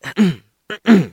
ahem.wav